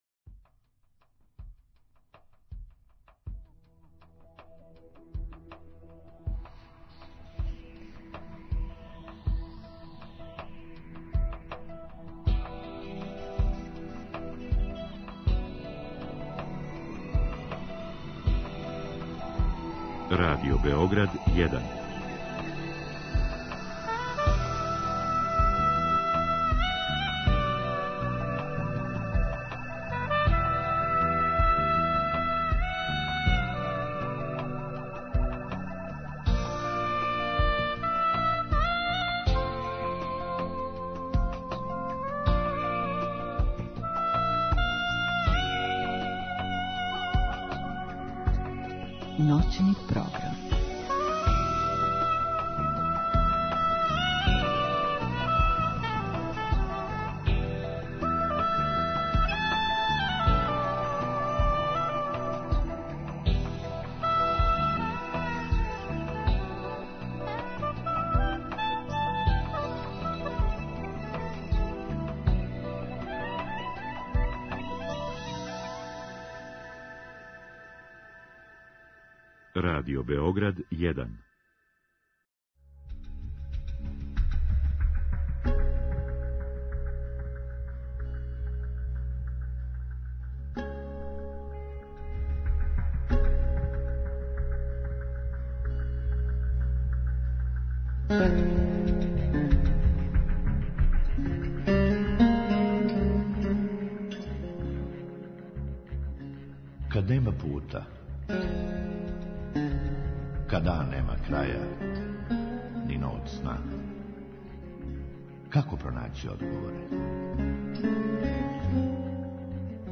У другом сату слушаоци у директном програму или путем Инстраграм странице емисије могу поставити питање у гошћи у студију.